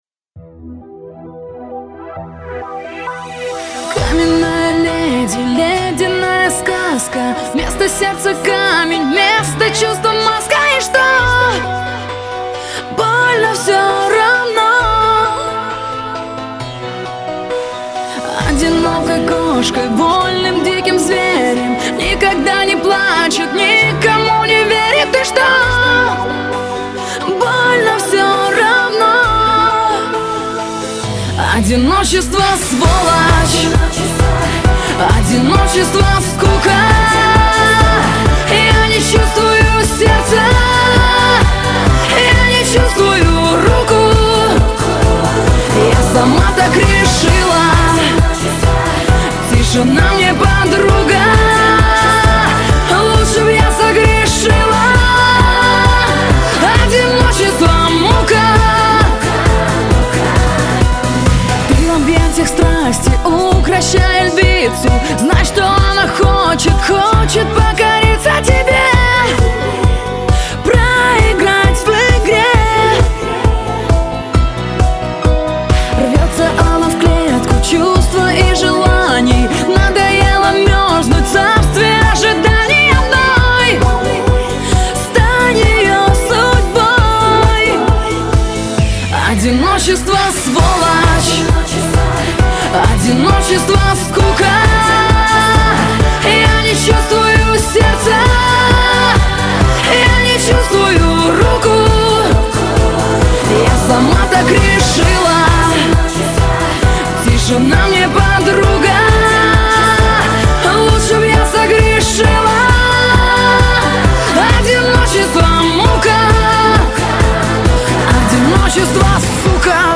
Жанр: Поп